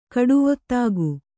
♪ kaḍuvottāgu